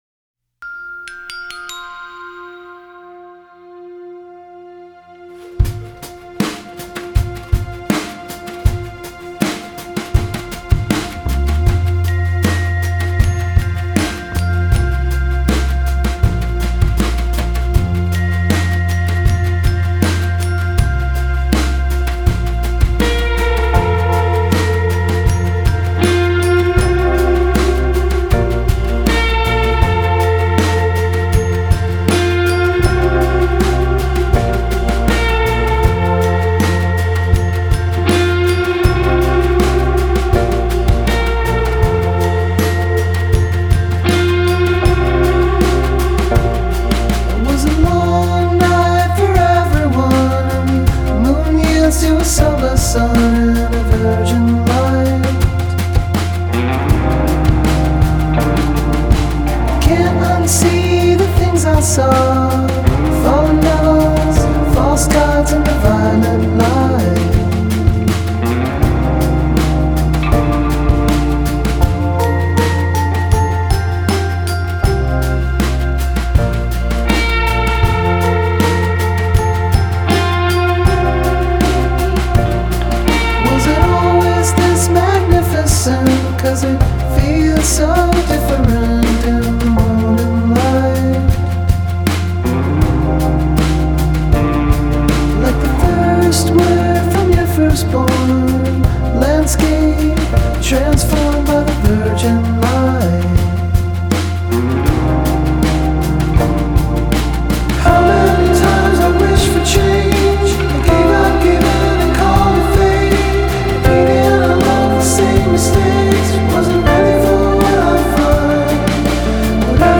Жанр: Alternative.